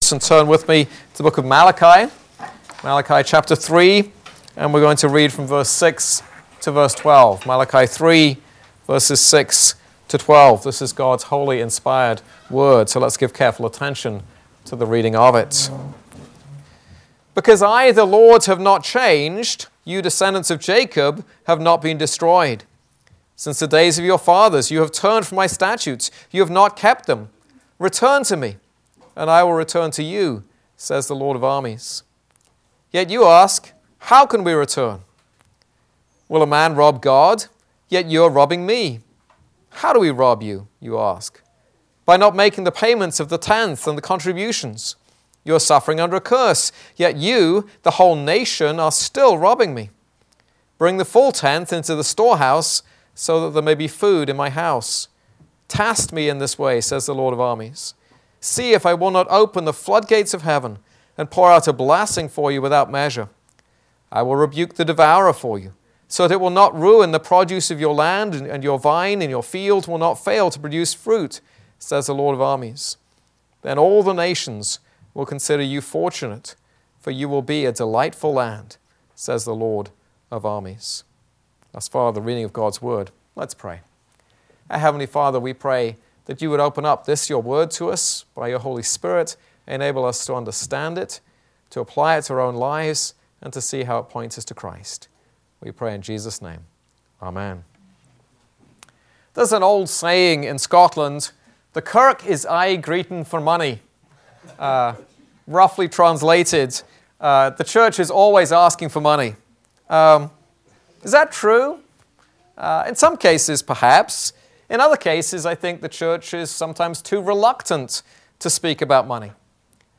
This is a sermon on Malachi 3:6-12.